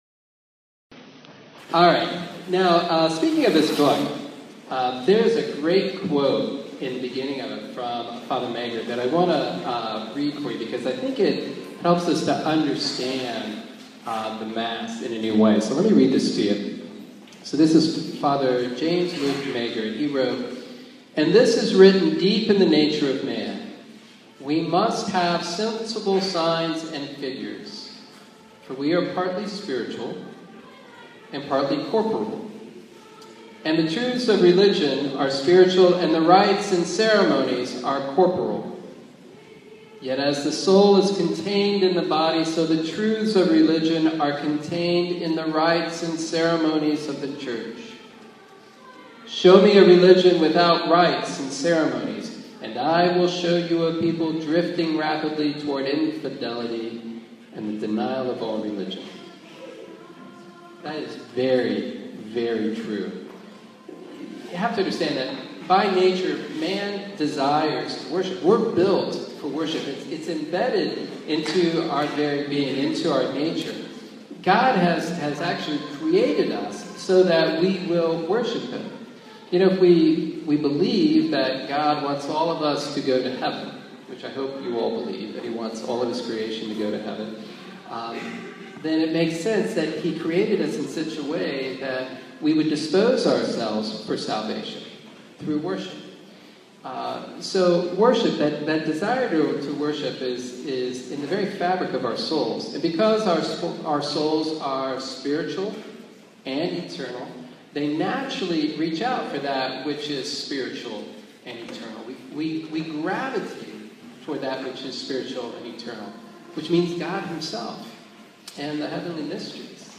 Latin Mass Introduction Class
On April 13, 2016, St. Ann parish and the Charlotte Latin Mass Community hosted a Latin Mass Introduction class to help familiarize newcomers to the Latin Mass and to provide an understanding of the meaning and symbolism when a priest offers the Traditional Mass.